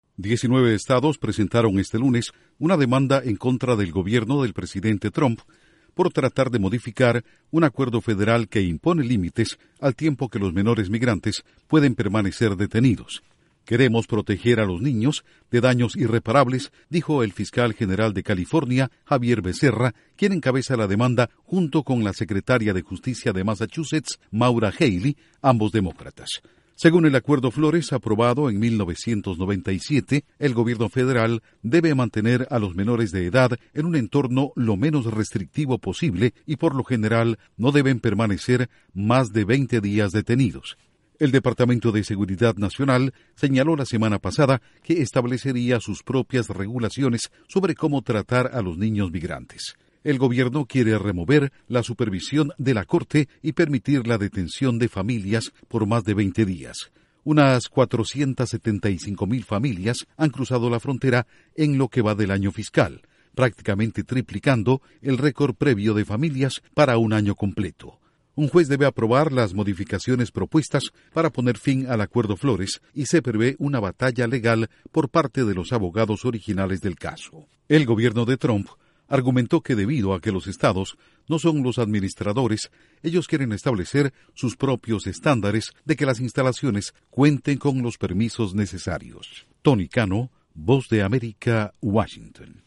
Diecinueve estados demandan a gobierno de Trump por reducir protecciones a migrantes. Informa desde la Voz de América en Washington